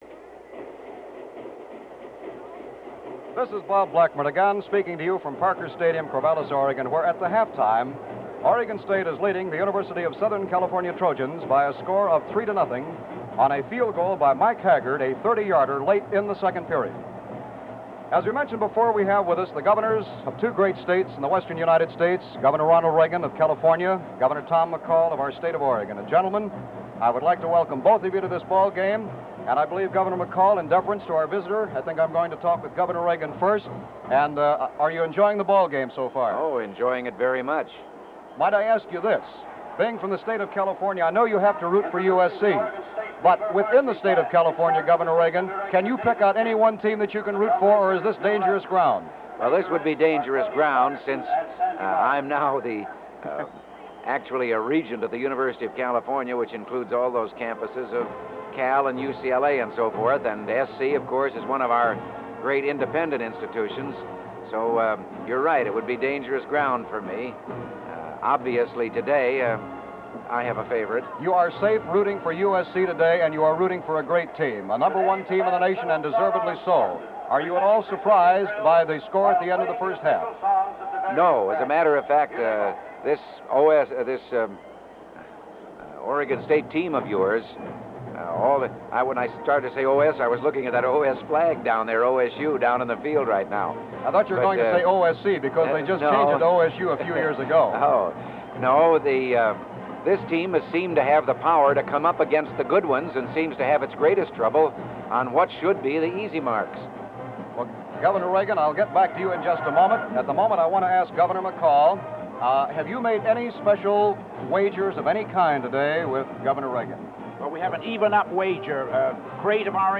Interview of Governor Reagan and Governor Tom McCall at halftime of OSU versus USC football game in Corvallis, Oregon
Includes audio of second half of game (2 Discs)